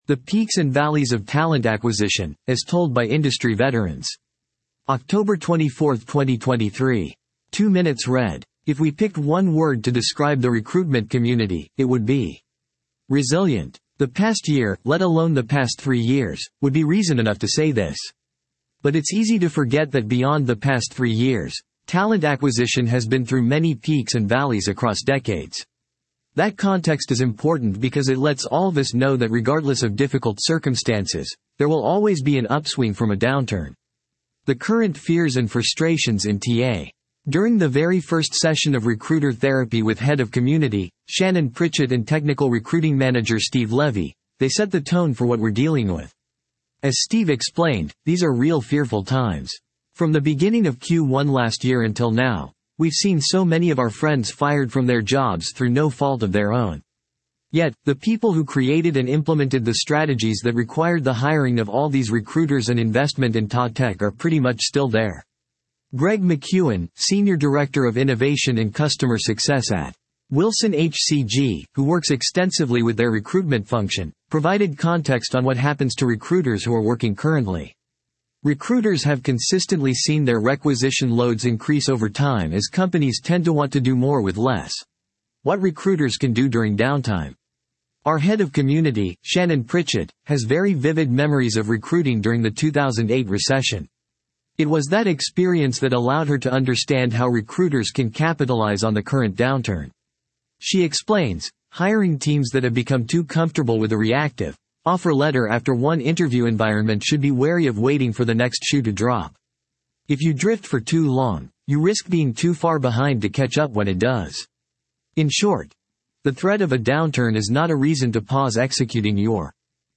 You can use this audio player to convert website page content into human-like speech. 11:11 00:00 / 14:00 1.0X 2.0X 1.75X 1.5X 1.25X 1.0X 0.75X 0.5X If we picked one word to describe the recruitment community, it would be resilient .